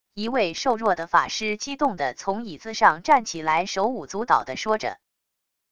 一位瘦弱的法师激动的从椅子上站起来手舞足蹈的说着wav音频